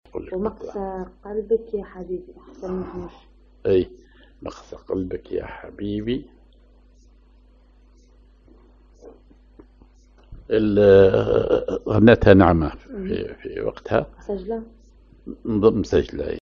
أغنية